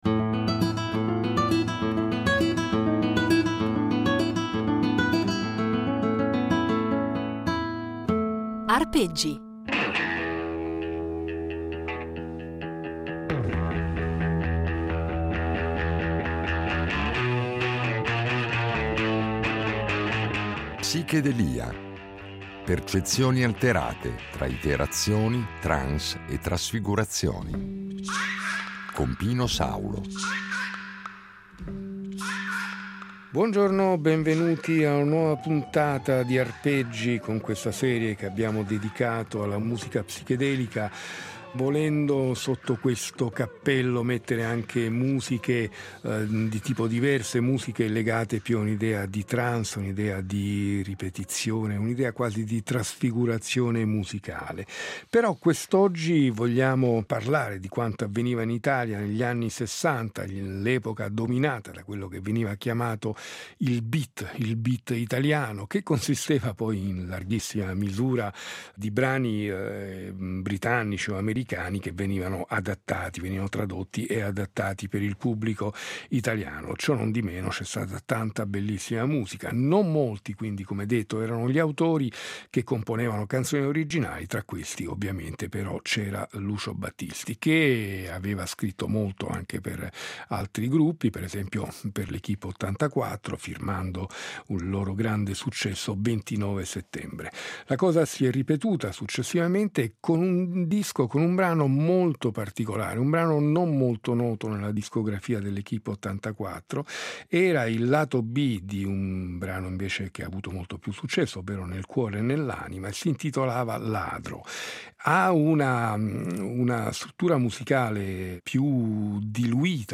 L’oggetto di questa serie di puntate è un lungo percorso che ci porterà ad esplorare e ad ascoltare musiche che in qualche misura possiamo definire come psichedeliche. Termine volutamente ambiguo, che va a indicare un insieme di musiche nate intorno alla metà degli anni ‘60 aventi in comune un riferimento alle droghe allucinogene, ma nelle quali la musica incorpora elementi provenienti da altre culture musicali - dall’oriente soprattutto, con l’utilizzo di strumenti come il sitar o le tabla - effetti sonori, tecniche di registrazione che pongono in qualche modo elevano lo studio di registrazione al rango di uno strumento al pari degli altri, con l’uso massiccio di loop, nastri al contrario, riverbero, e ancora elementi del jazz modale e più sperimentale, droni, il tutto condito da luci stroboscopiche e testi surreali o con riferimenti più o meno velati a esperienze allucinatorie.